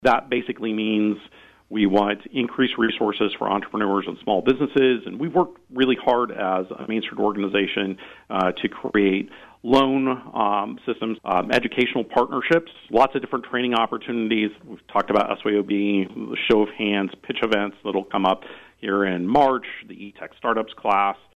an interview on KVOE’s Talk of Emporia Monday morning